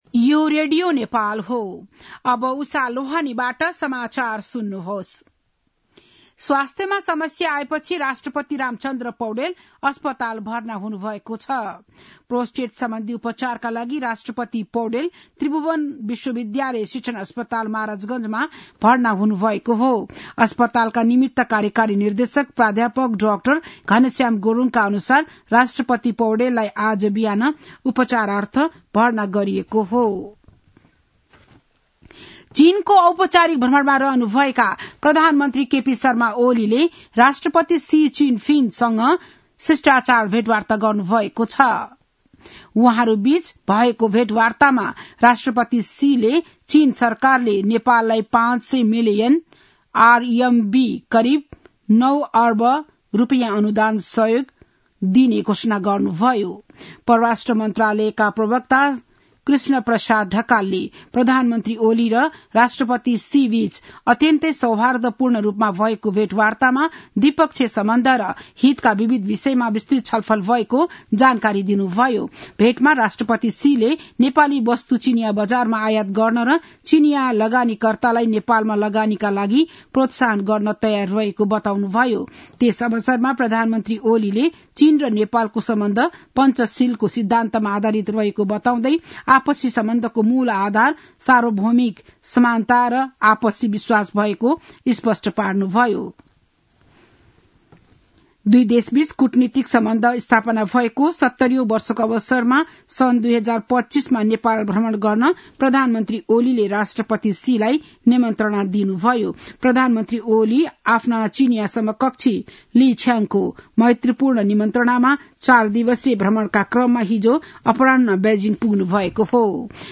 बिहान ११ बजेको नेपाली समाचार : २० मंसिर , २०८१
11-am-nepali-news-1-3.mp3